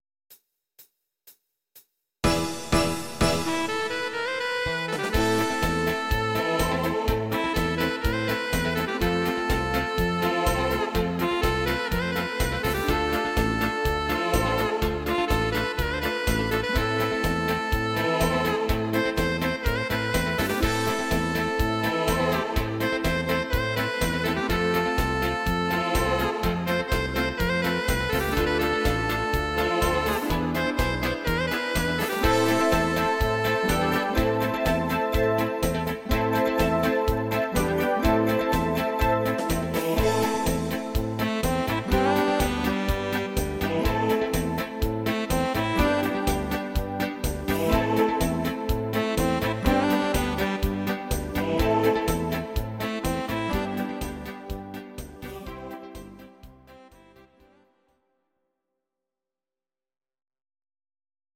These are MP3 versions of our MIDI file catalogue.
instr Saxophon